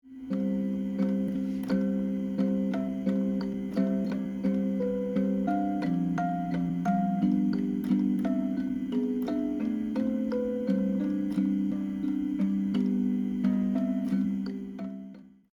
例如本次示範調整的曲子，其接近尾聲的部分，因為經過前面的音域調整，旋律和伴奏的音域會打在一起，這時我把這段旋律移高八度，低音部分不動，前後的效果比較如下：
1-低音.mp3